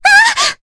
Cassandra-Vox_Damage_jp_03.wav